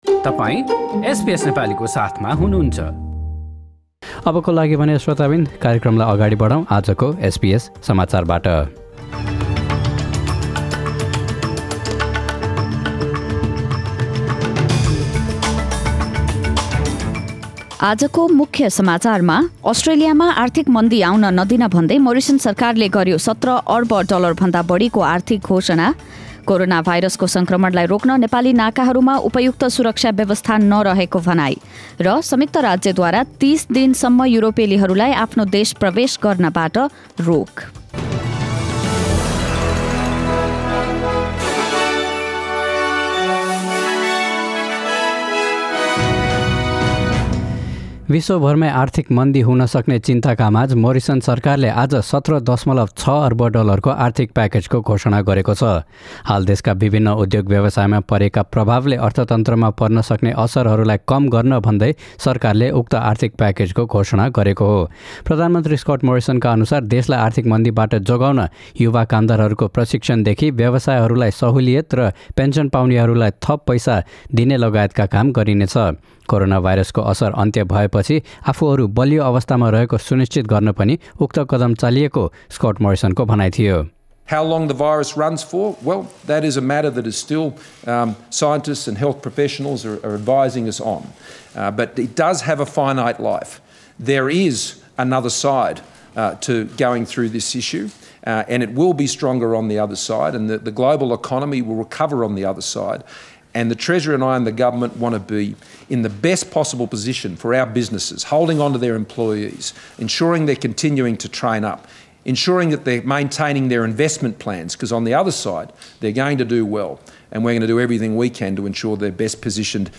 एसबीएस नेपाली अस्ट्रेलिया समाचार:बिहिवार १२ मार्च २०२०